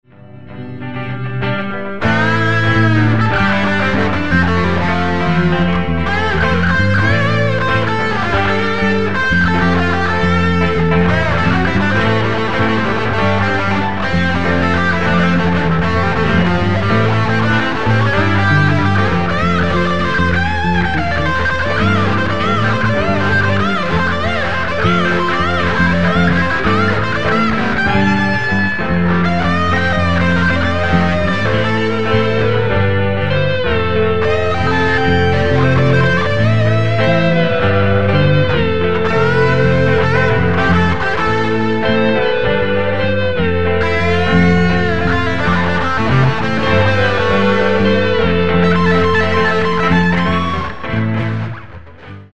Je to cele bez spevu a len gitary (vrsvene)
(ale prvy ton je vytiahnuty presne...no skoro)
Gitara: Jolana Iris
Efekt: Zoom 505 II